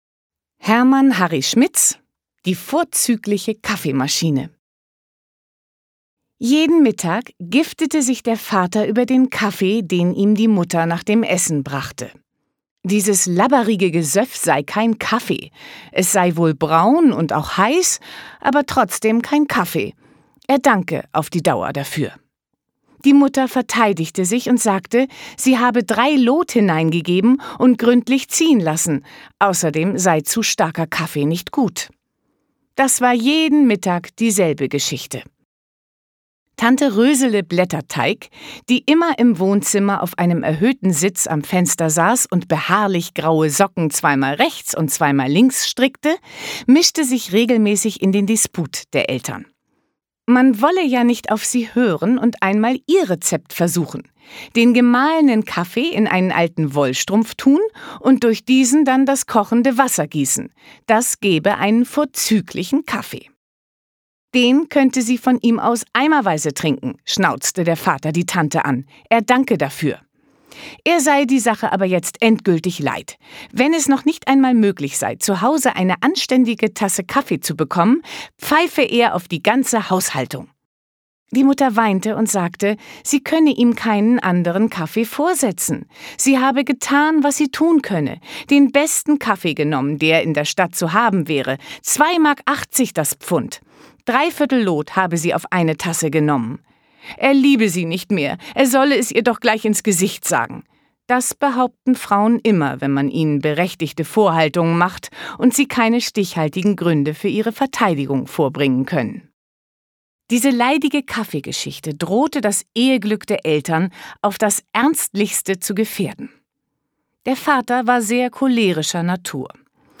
Katharina Thalbach, u.v.a. (Sprecher)
Schlagworte Anthologie • Belletristik: allgemein und literarisch • Hörbuch; Literaturlesung • Hörerlebnis • Kurzgeschichten • Kurzgeschichte / Short Story • Lyrik: Anthologien